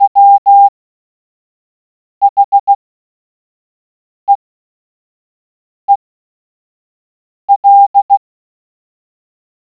The pattern seems familiar — it’s Morse code.
Objective Your task is to decode the Morse code signal coming from the radio.
the-beeping-signal.wav